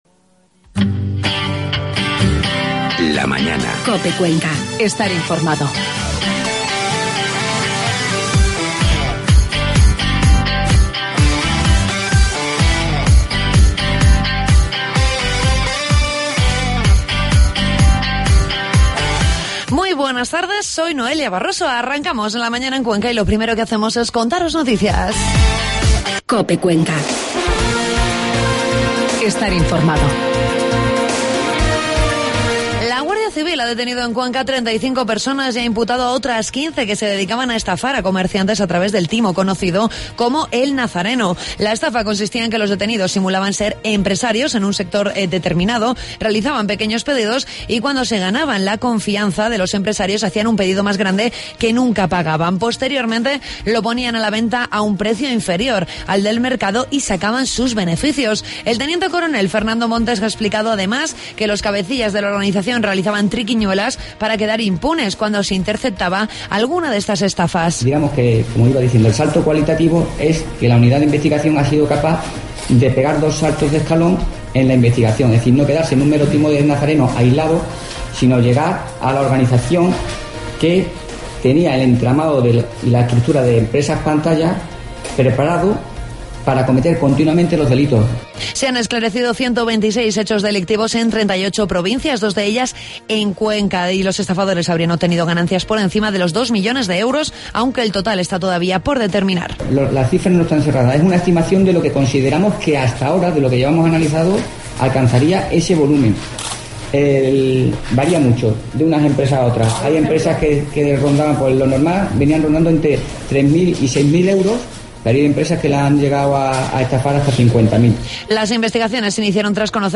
Escuchamos en directo dos canciones en acústico del grupo Rey Sol que este sábado actuará en la sala Babylon.